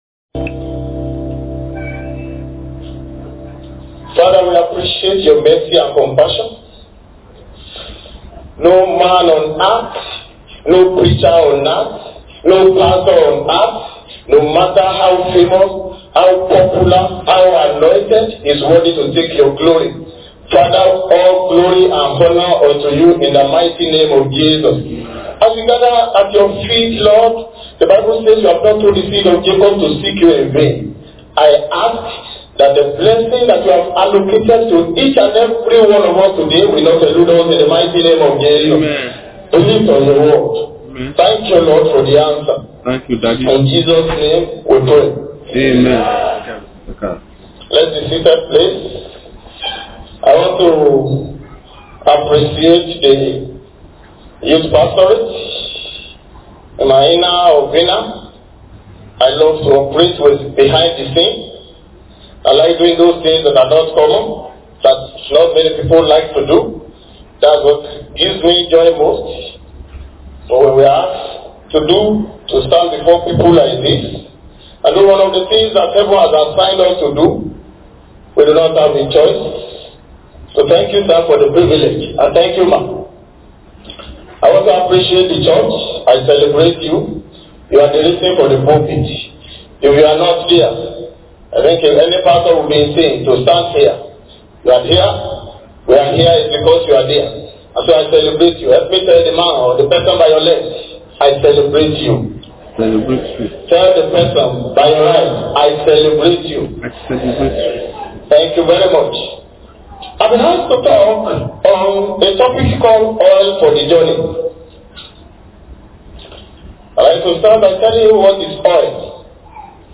Sermon
Posted in Youth Service